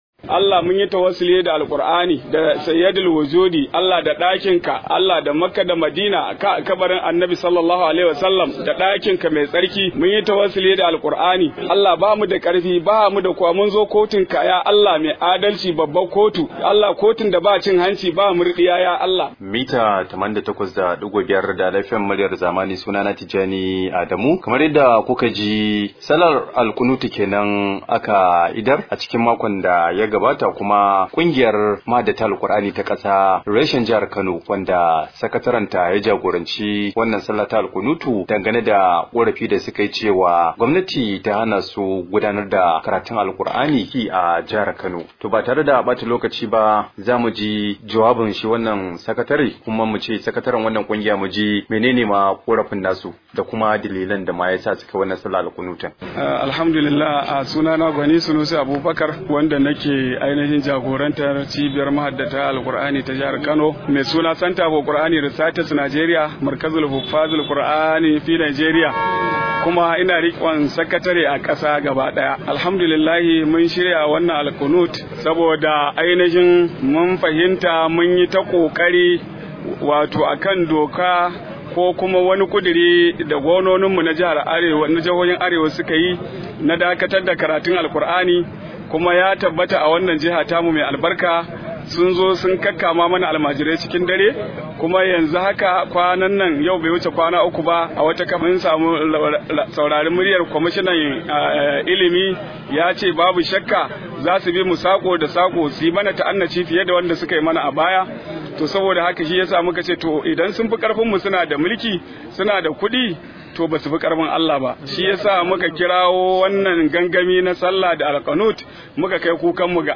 Rahoto: Bama goyan gwamnati kan hana karatun allo a Kano – Kungiyar mahaddata